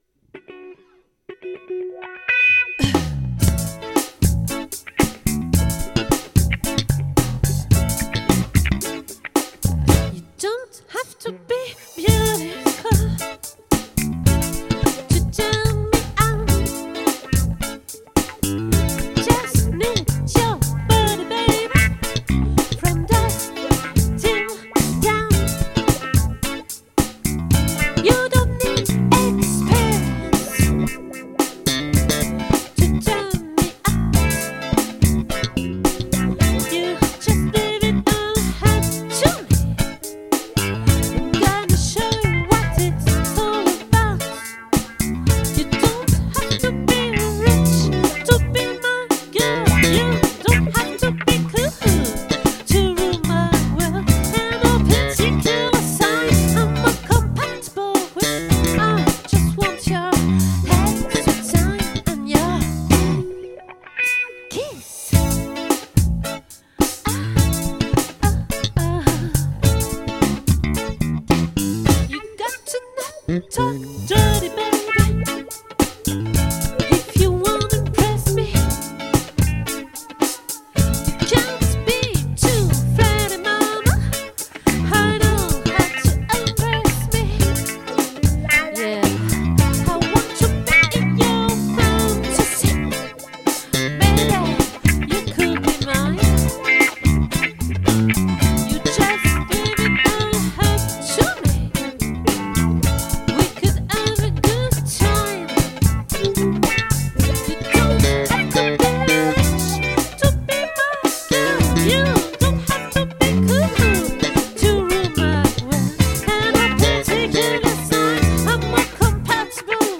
🏠 Accueil Repetitions Records_2024_01_24